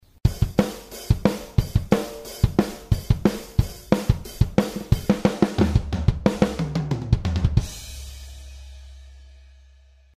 Концовка на барабанах